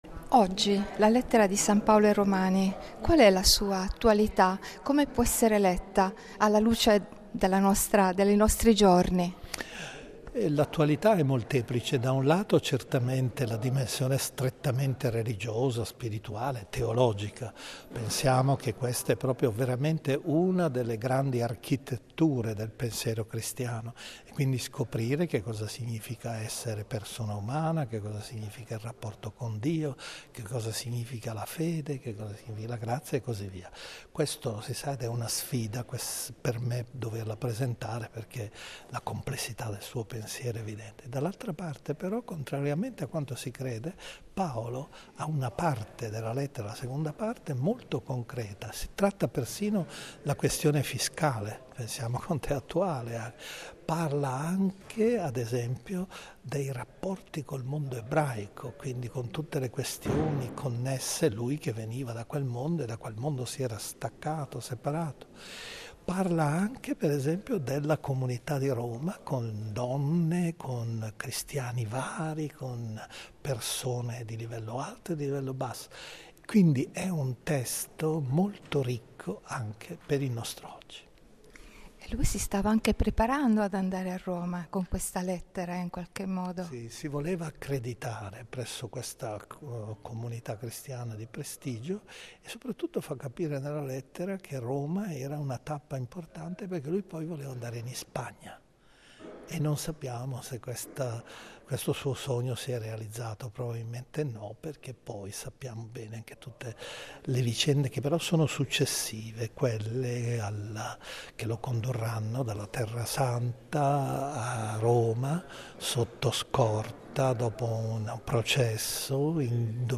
Ascolta l’intervista al cardinale Gianfranco Ravasi, fondatore di “Il cortile dei gentili”